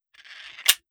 38 SPL Revolver - Spinning Barrel 002.wav